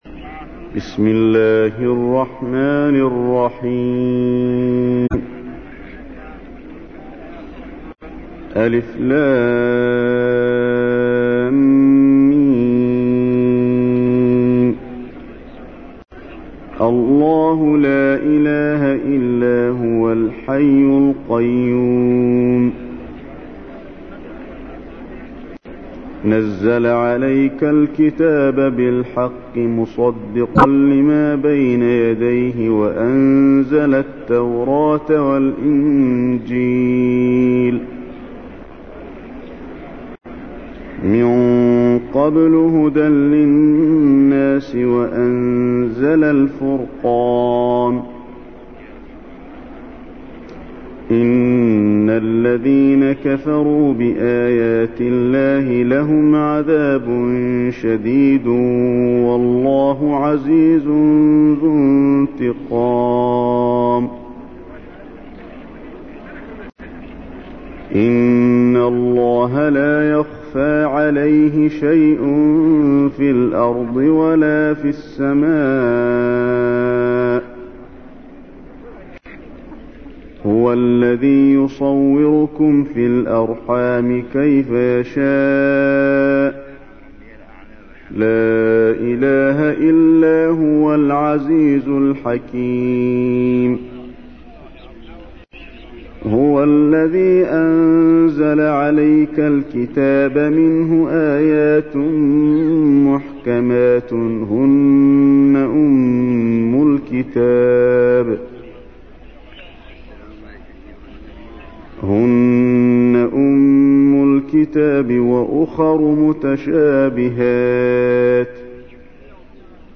تحميل : 3. سورة آل عمران / القارئ علي الحذيفي / القرآن الكريم / موقع يا حسين